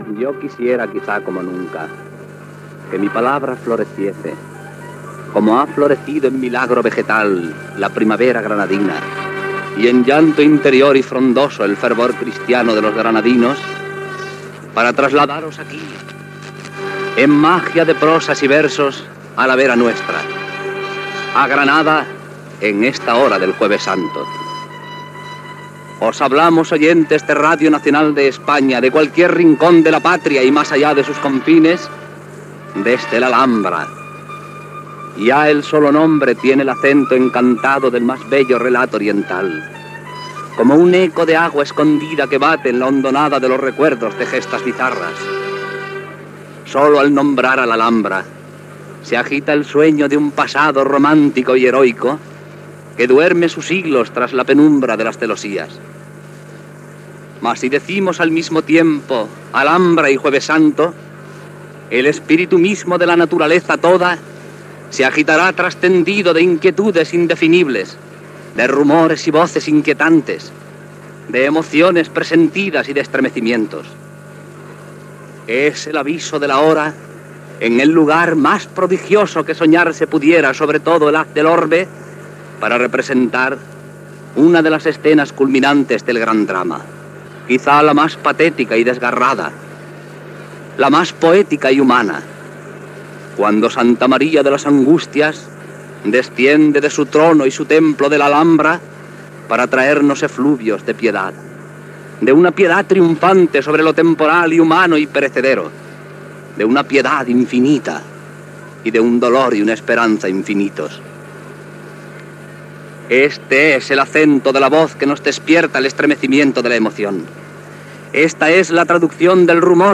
Transmissió de la Setmana Santa de Granada